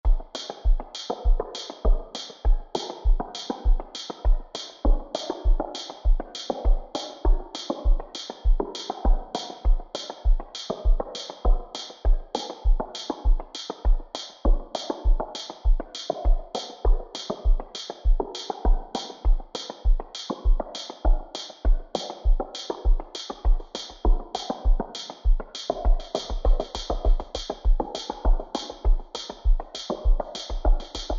Elektron Gear Machinedrum
It could be anything but it sounds like you have some high pass filter with resonance and then this is going into the delay.
You definitely pitched everything down (the bass drum is tuned way too low in the 2nd example).